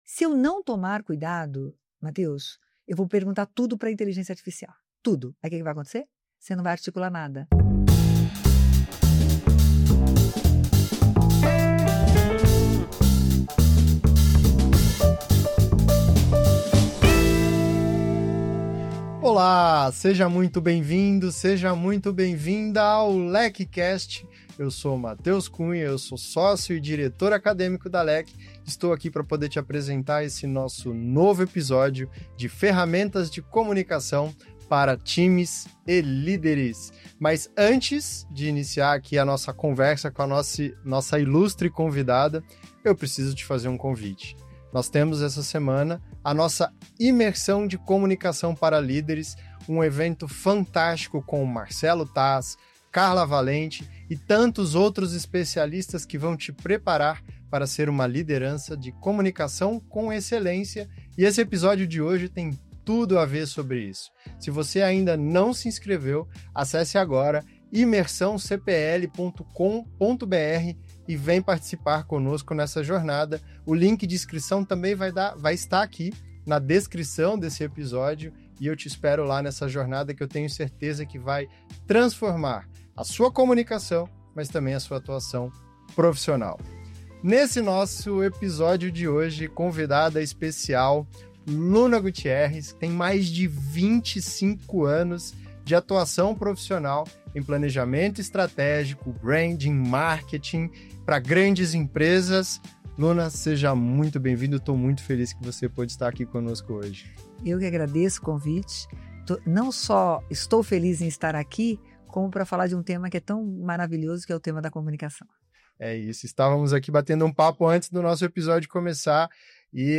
especialista em comunicação interpessoal, estrategista de marcas e professora, para um bate-papo imperdível sobre comunicação corporativa.